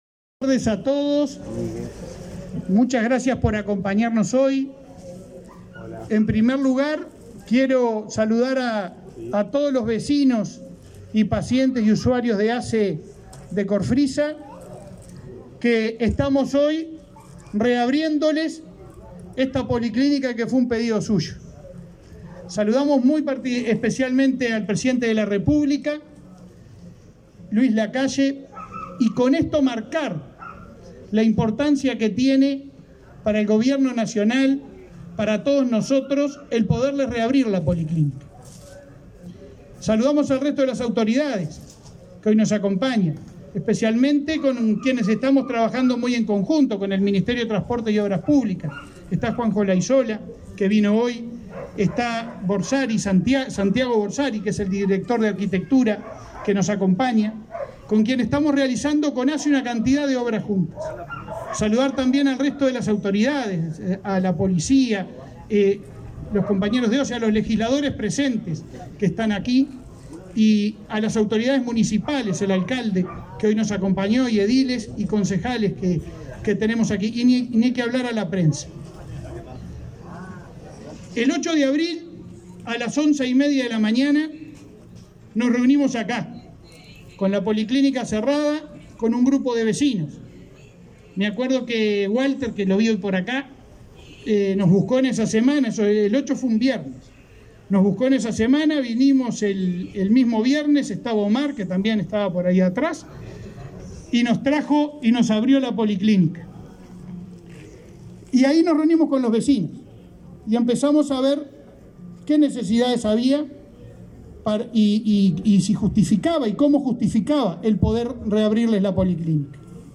Palabras del presidente de ASSE, Leonardo Cirpiani 11/08/2022 Compartir Facebook X Copiar enlace WhatsApp LinkedIn Con la presencia del presidente de la República, Luis Lacalle Pou, fue inaugurada, este 11 de agosto, la policlínica Corfrisa, en Las Piedras, departamento de Canelones. El presidente de la Administración de los Servicios de Salud del Estado (ASSE) realizó declaraciones en el evento.